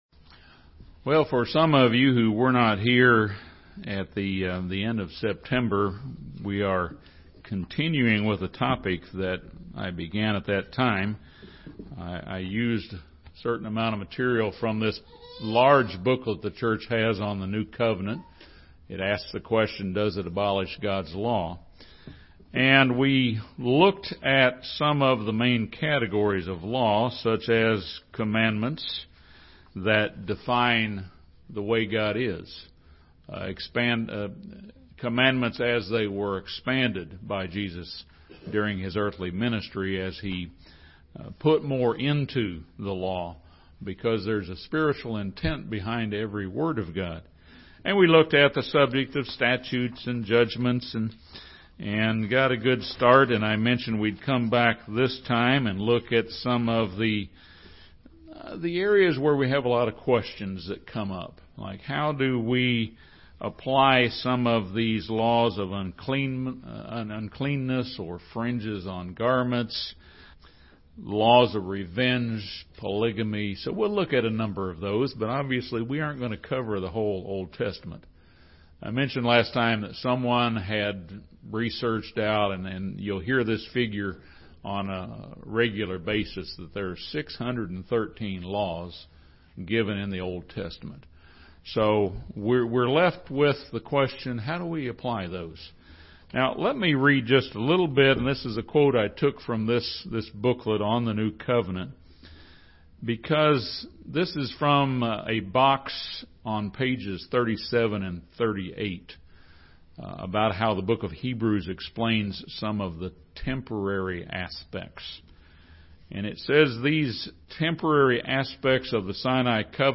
This sermon discusses a number of figurative ceremonial laws and how they may apply in principle in today's Church. The sermon considers laws of purification, laws of quarantine, wearing phylacteries, laws of revenge and polygamy.